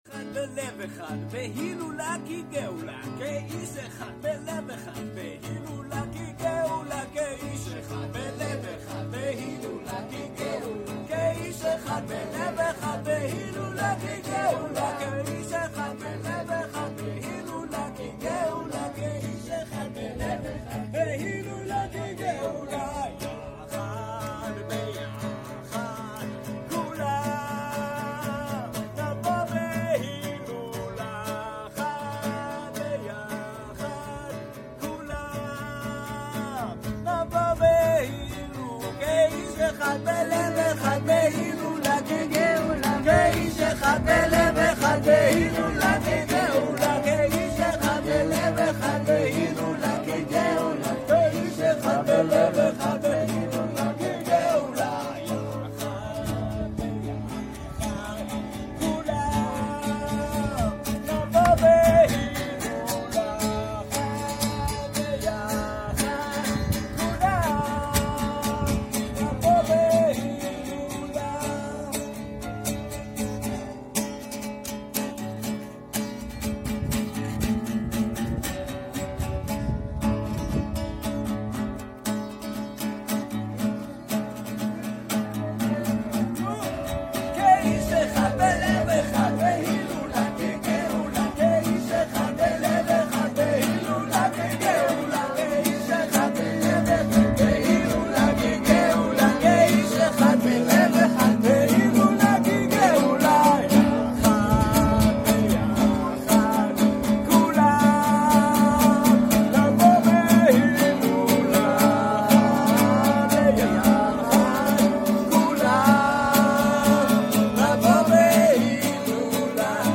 שיר מנטרה חדש לראשונה מול קהל אהוב